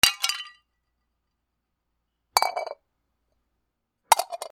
陶器を割る 素焼き
/ H｜バトル・武器・破壊 / H-80 ｜陶器